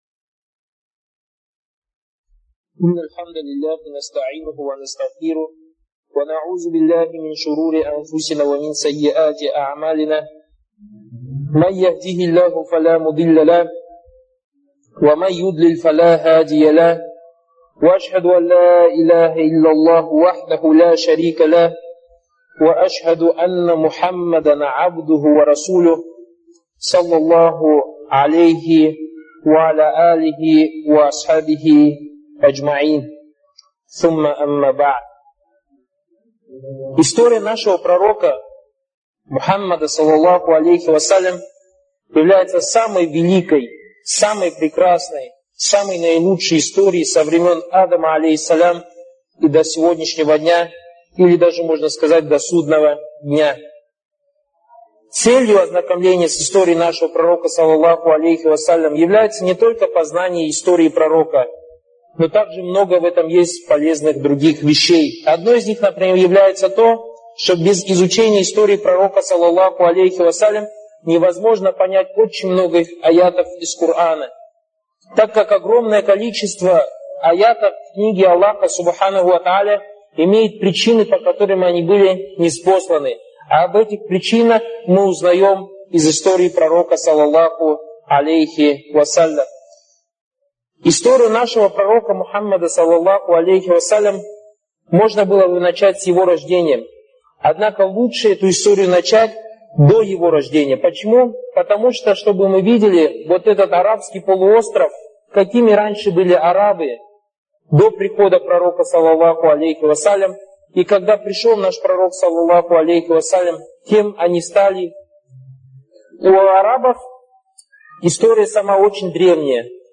лекции Торик Суейдана (были приняты во внимание его ошибки, на которые указали учёные).